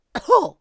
traf_damage1.wav